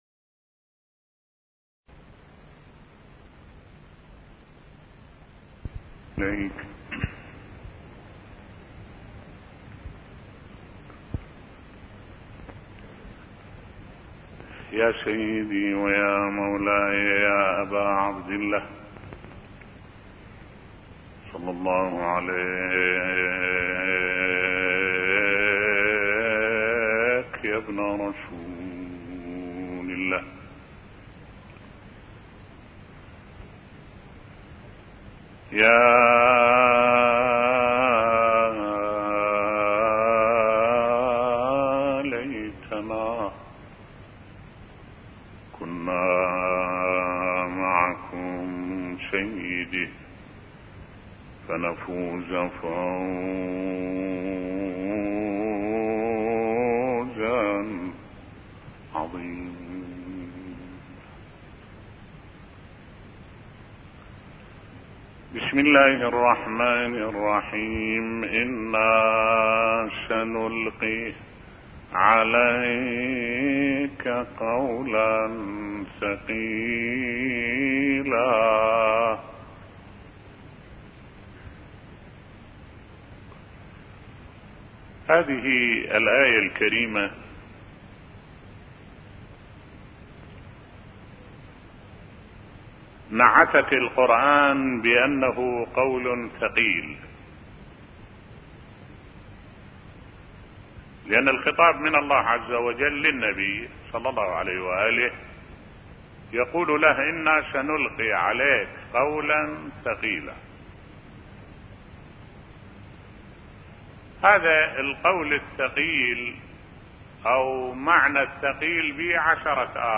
ملف صوتی انا سنلقي عليك قولا ثقيلا بصوت الشيخ الدكتور أحمد الوائلي